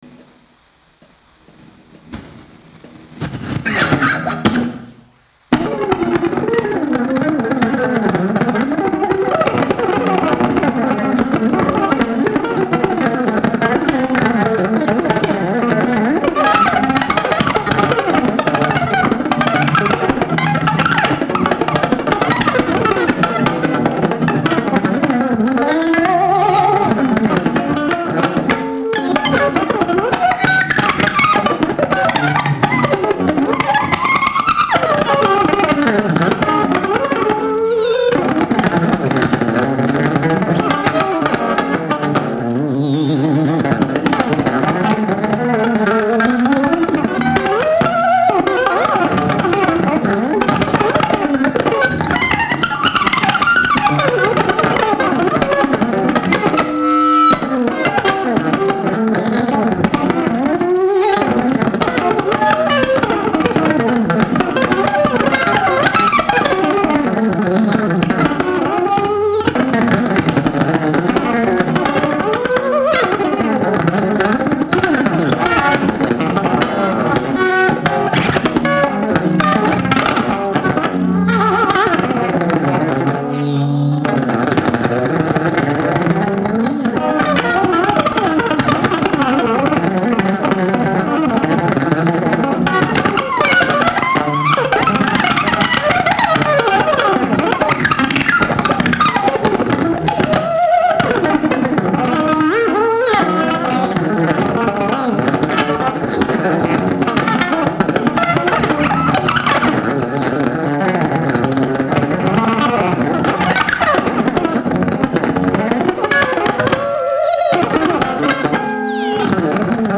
レコーディングはZT-AmpのLunchboxの爆音を、ステレオ・マイクで収音しておこなわれました。
エフェクターは特殊なファズと、コンプレッサー、それに僅かなリバーブのみです。
ギター愛好家の方々にはもちろん、現代音楽、先端的テクノ、実験音楽をお好きな方々にもお薦めのアルバムです。